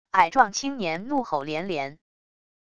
矮壮青年怒吼连连wav音频